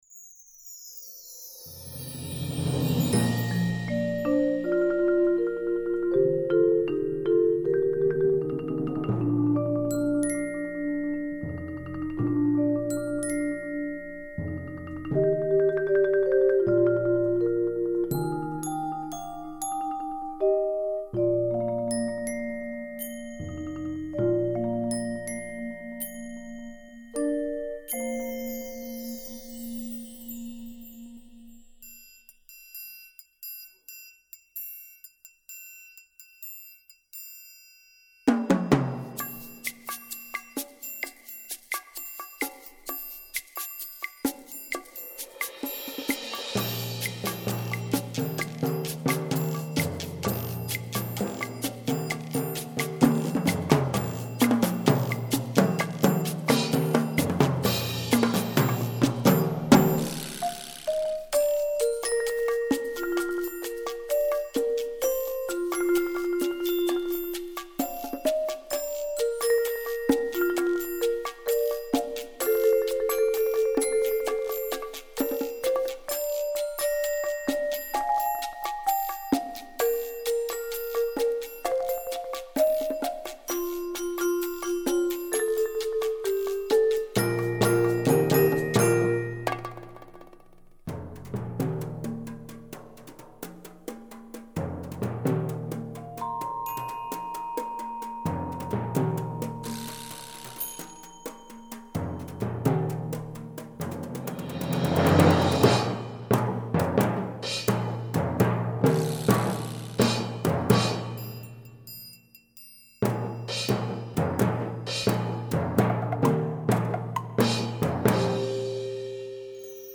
Voicing: Percussion Nonet